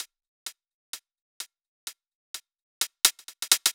VFH3 Mini Kits Drums